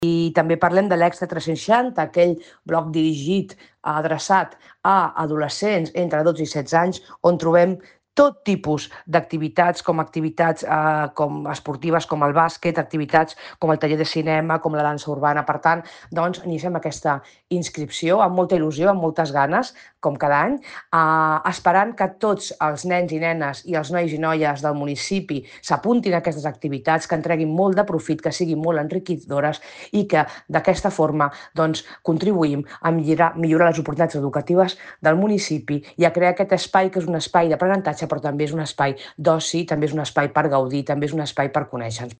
Soledad Rosende, regidor d'Ensenyament